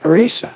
synthetic-wakewords
ovos-tts-plugin-deepponies_Joe Biden_en.wav